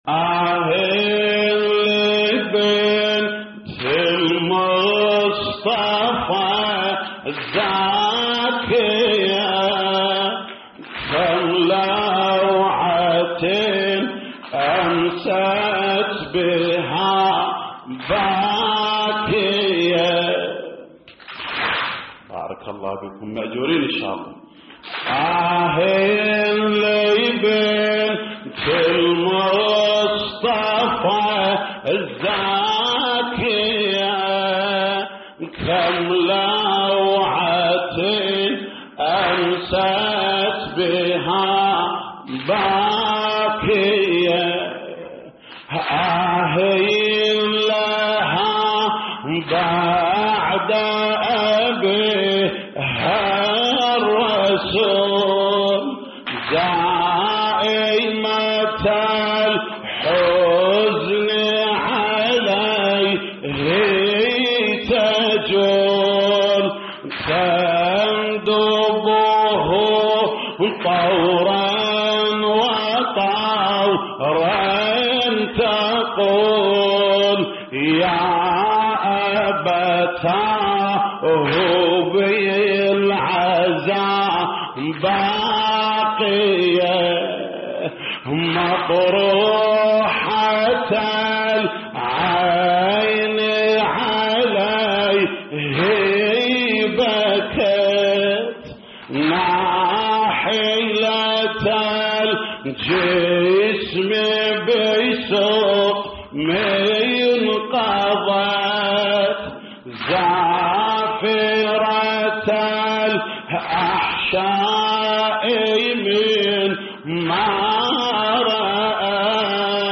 تحميل : آه لبنت المصطفى الزاكية كم لوعة أمست بها باكية / الرادود جليل الكربلائي / اللطميات الحسينية / موقع يا حسين